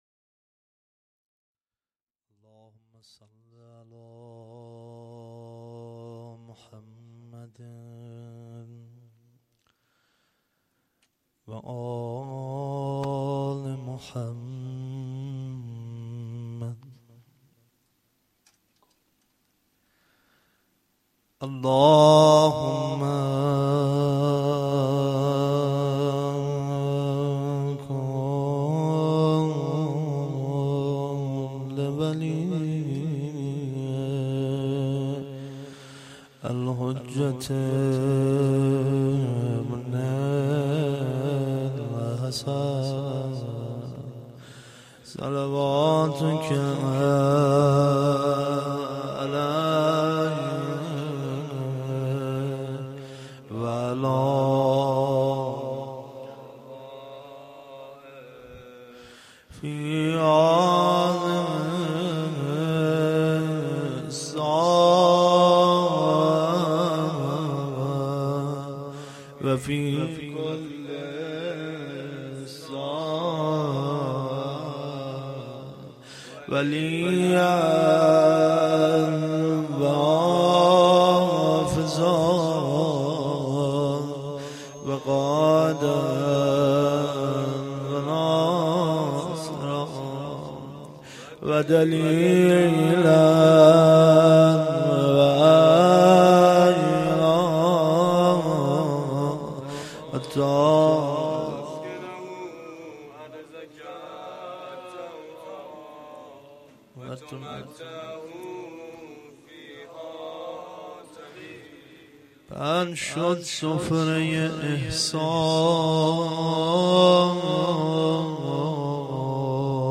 روضه1
roze1-Rozatol-abbas.Esteghbal-az-Ramezan.mp3